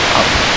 The files are for speech plus noise, and only noise.